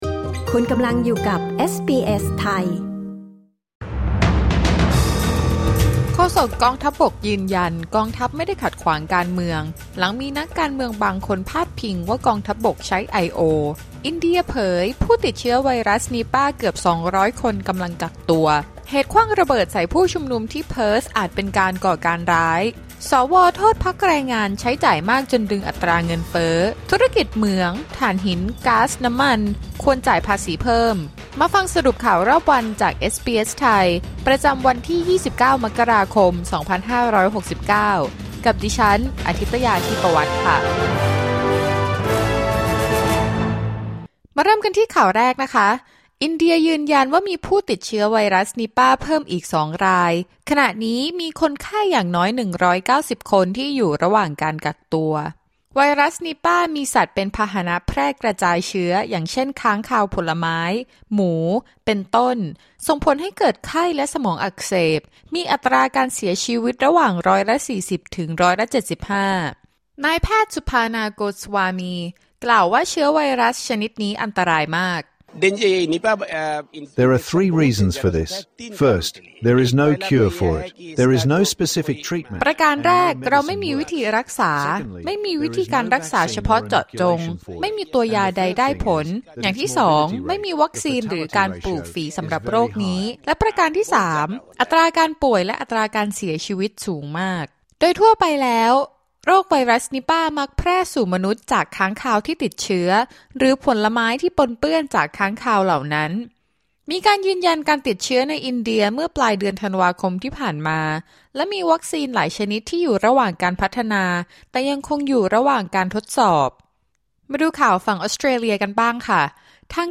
สรุปข่าวรอบวัน 29 มกราคม 2569